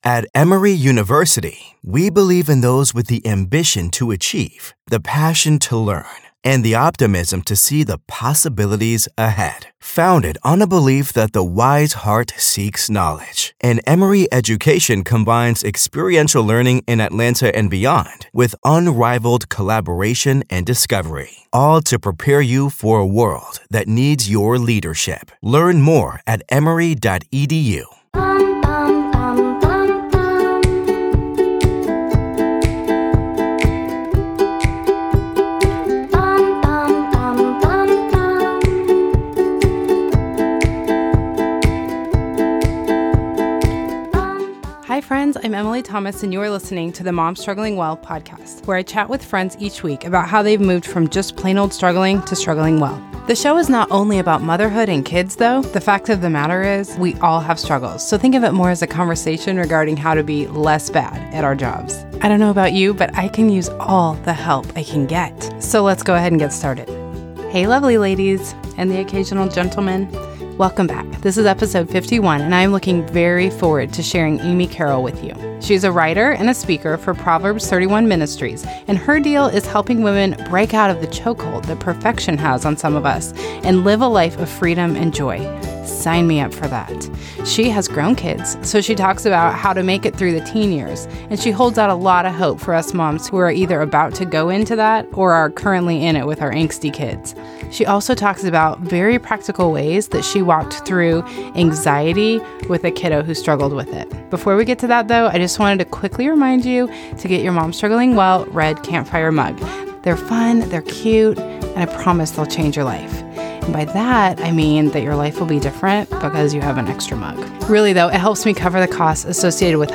You're really going to get a lot out of this chat.